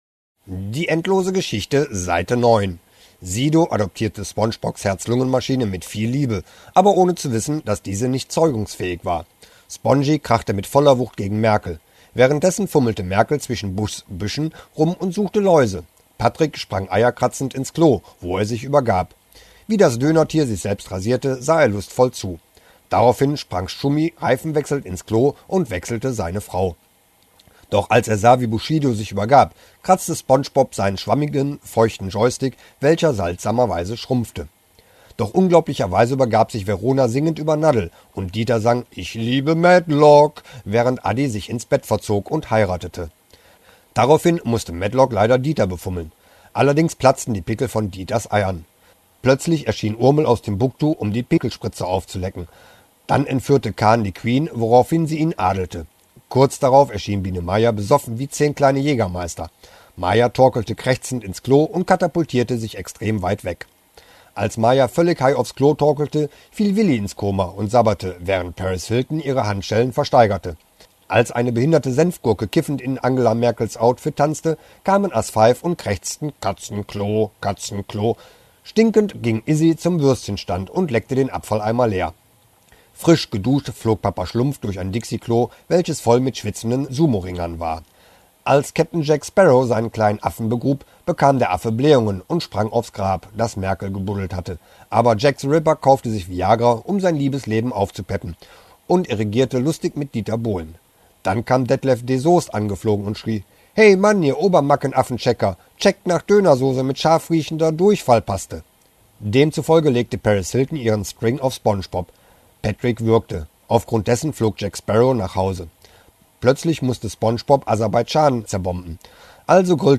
Das Hörbuch zur endlosen Story, Seite 9